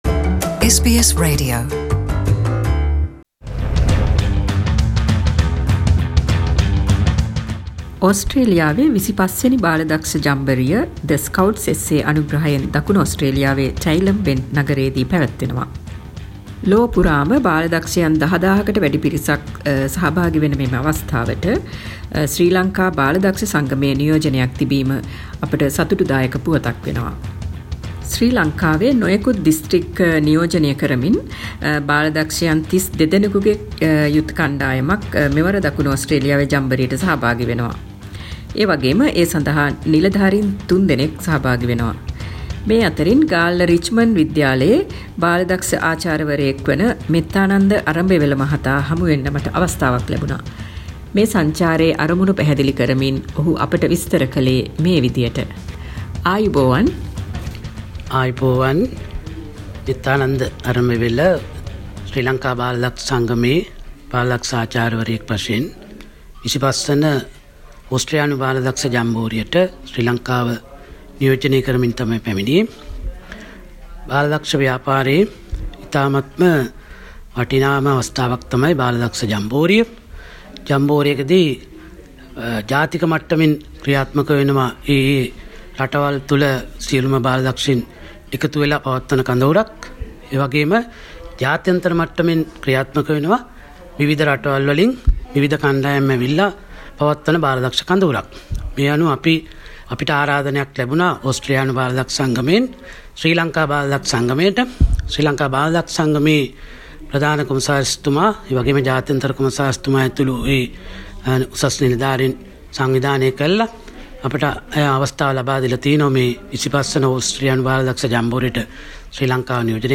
SBS සිංහල වැඩසටහන